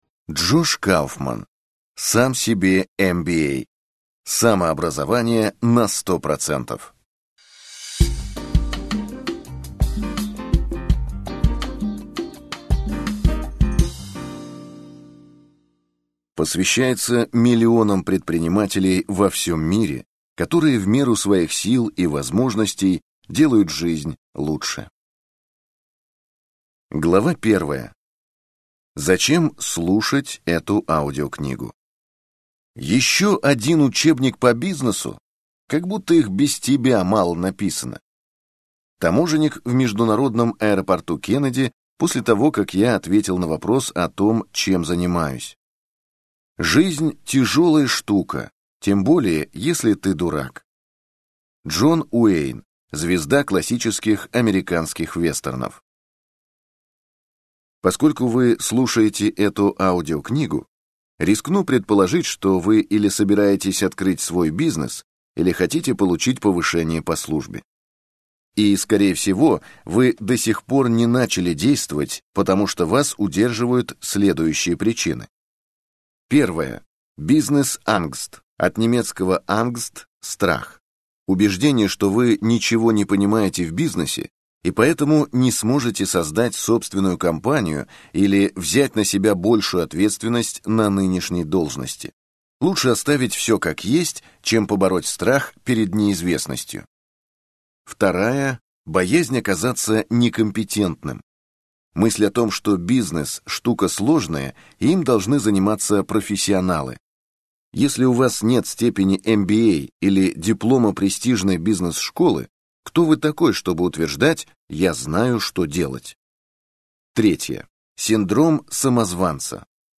Аудиокнига Сам себе MBA. Самообразование на 100% - купить, скачать и слушать онлайн | КнигоПоиск